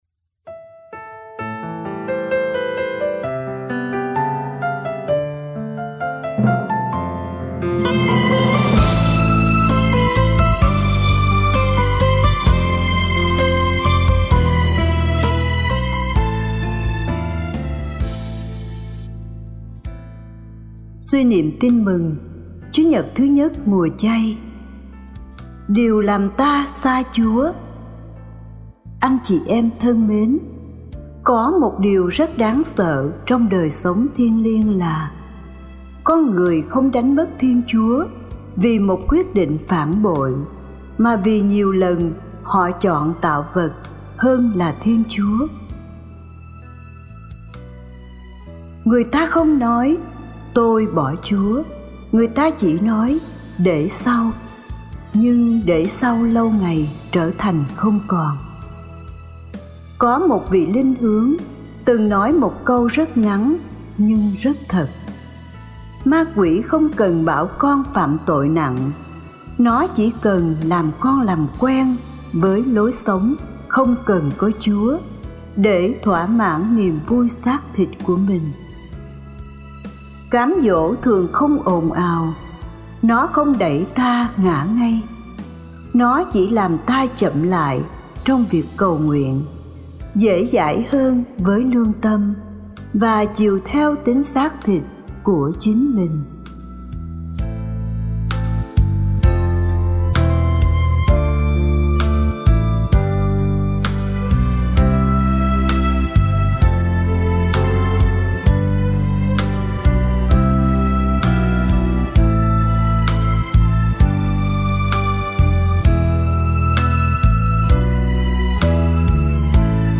Điều làm ta xa Chúa (Bài giảng lễ Chúa nhật 1 mùa chay A - 2026)